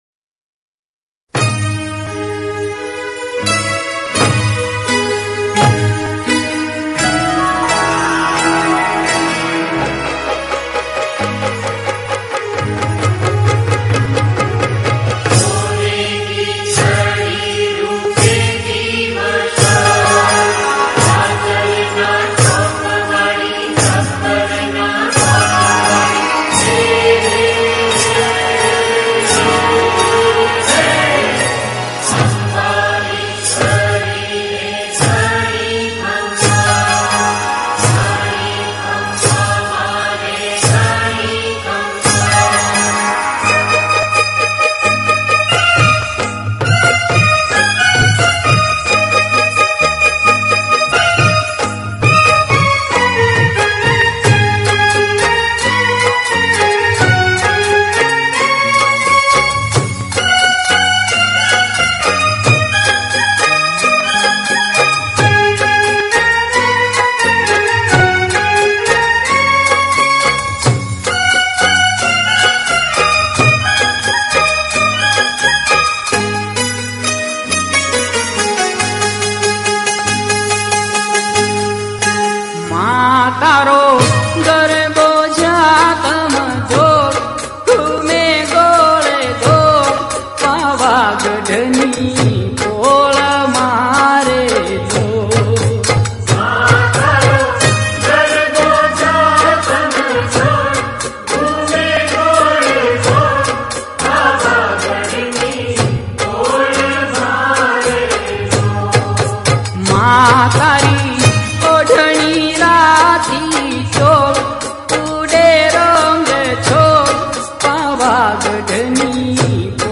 Non Stop Dandiya Mix